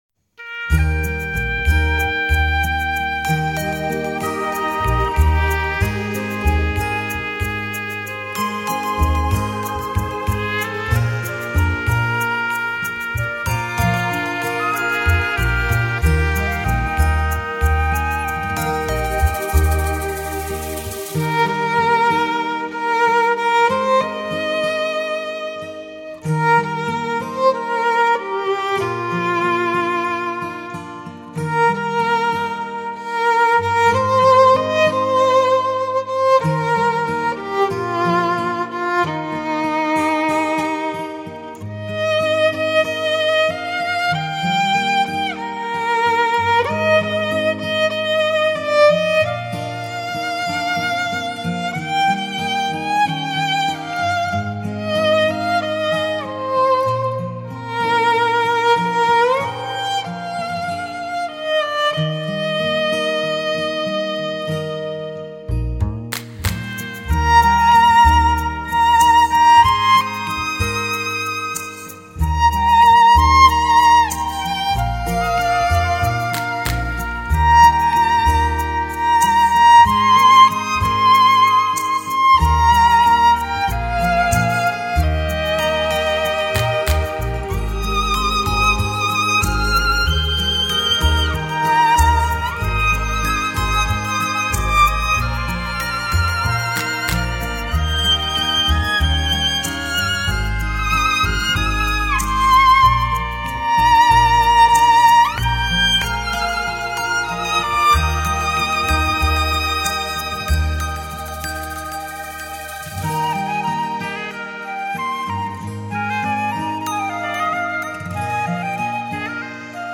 采用DSD直接刻录技术制作
小提琴、大提琴、钢琴、长笛、二胡等中西乐器演绎经典，